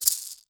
maraca.wav